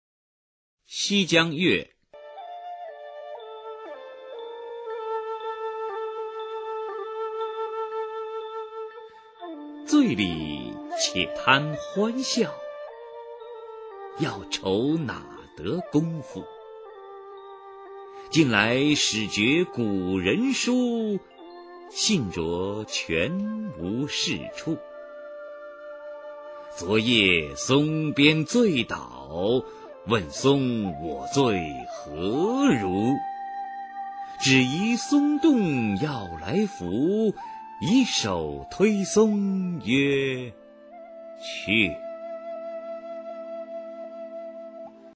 [宋代诗词诵读]辛弃疾-西江月（男） 宋词朗诵